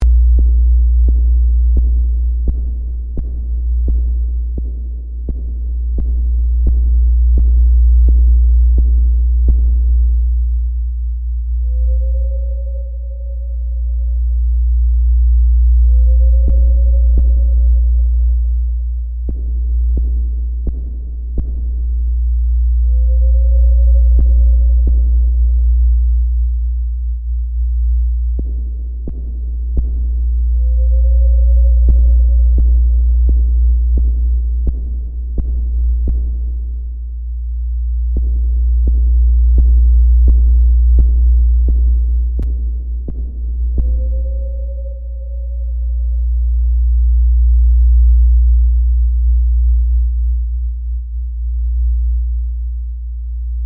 Sound that evokes a feeling of unease and possible fear, like there is someone (or something) unknown in the darkness.
This was designed as an installation, where a camera picks up movement which triggers the sounds.
There are three layers of sound:
1. The base layer is a low humming that plays continuously. It is a deep ominous sound.
2. The second layer is echoing footsteps, to suggest being in a large room and having someone else there. This sound only plays when there is no movement to give the feeling that the participant is only safe when they’re moving.
3. The third is a whine that sounds distant. This sound plays repeatedly with a small interval between each whine and is turned on and off by movement.